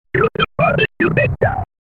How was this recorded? Specifically, it has a grid overlaid onto the marks that correspond to sound, and we need to remove the grid; otherwise we'll hear it too. The frequency scale is logarithmic and runs, as nearly as I'm able to estimate, from 62.5 Hz at the bottom to 5000 Hz at the top.